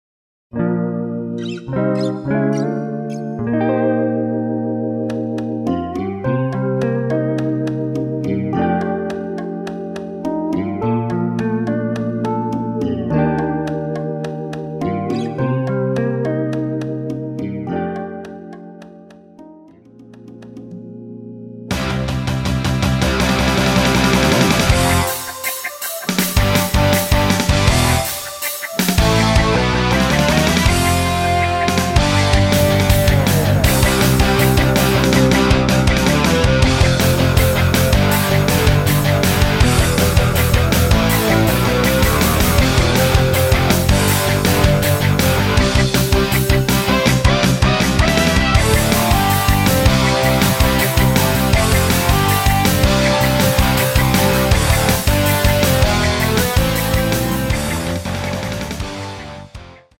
Rock으로 리메이크한 곡
Bb
노래방에서 음정올림 내림 누른 숫자와 같습니다.
앞부분30초, 뒷부분30초씩 편집해서 올려 드리고 있습니다.